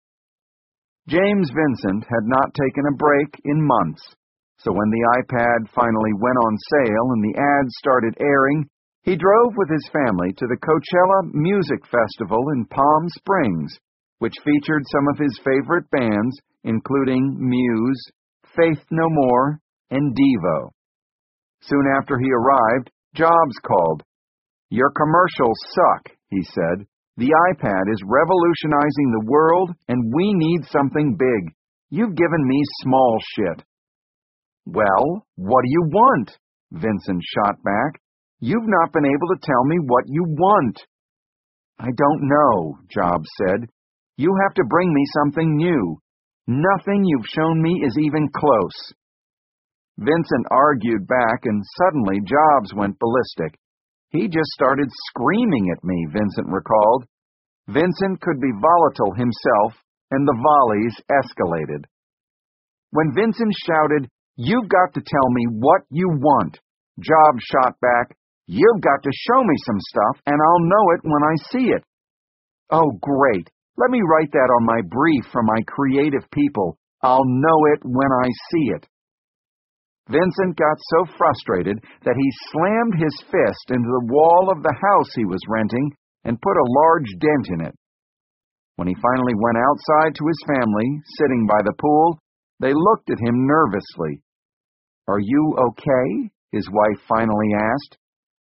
在线英语听力室乔布斯传 第692期:广告(2)的听力文件下载,《乔布斯传》双语有声读物栏目，通过英语音频MP3和中英双语字幕，来帮助英语学习者提高英语听说能力。
本栏目纯正的英语发音，以及完整的传记内容，详细描述了乔布斯的一生，是学习英语的必备材料。